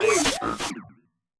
Cri de Gambex dans Pokémon Écarlate et Violet.